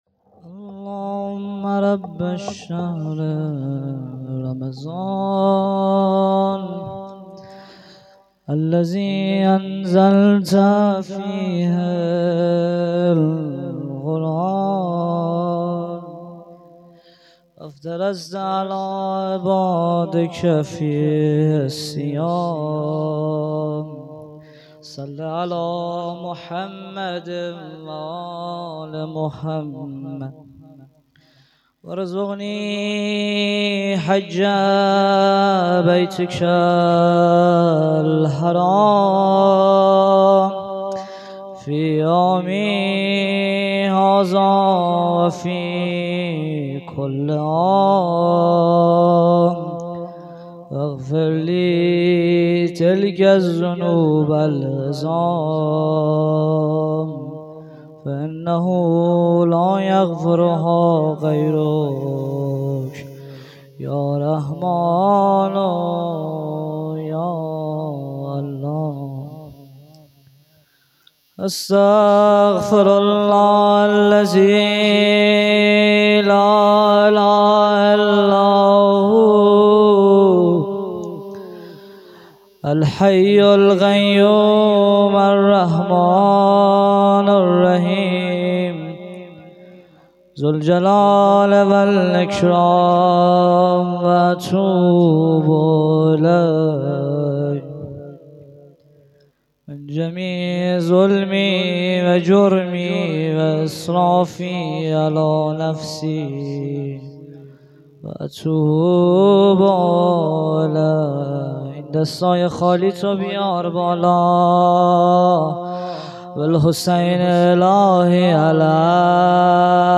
روضه هفتگی